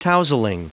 Prononciation du mot tousling en anglais (fichier audio)
Prononciation du mot : tousling